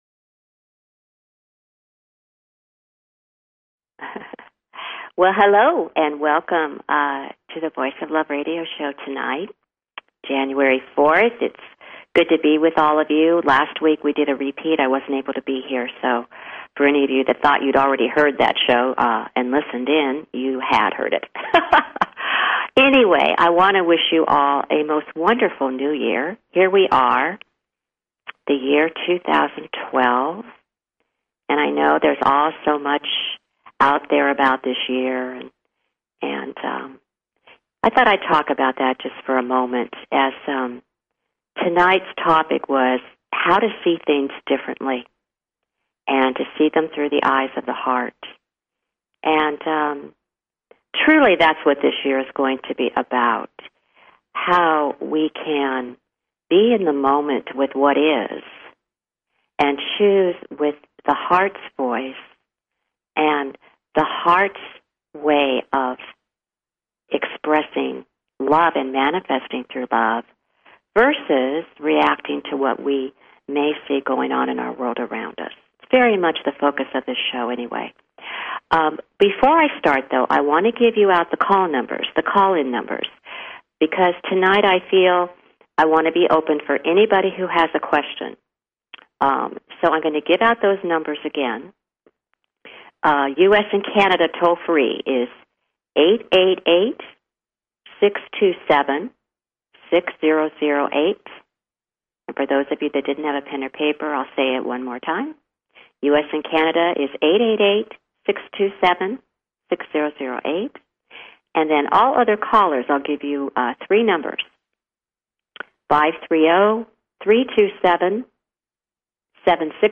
Talk Show Episode, Audio Podcast, The_Voice_Of_Love and Courtesy of BBS Radio on , show guests , about , categorized as